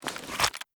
pistol_holster.ogg